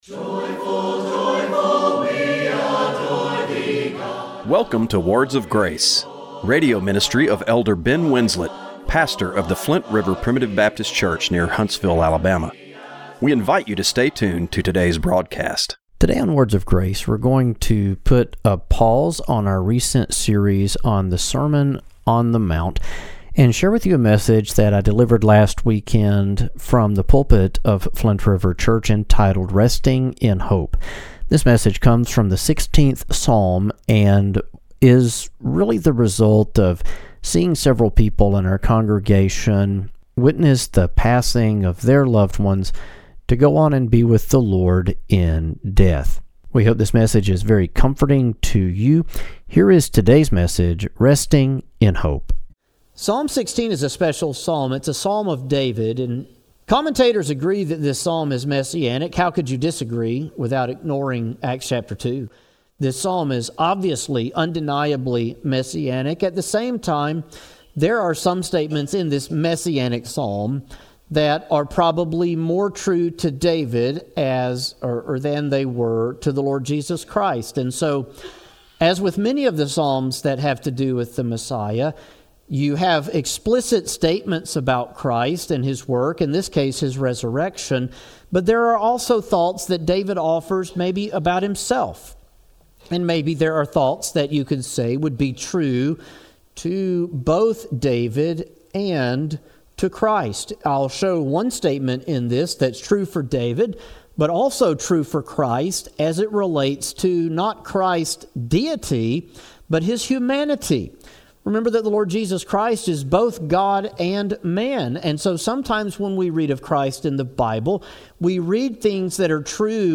Radio broadcast for March 2, 2025.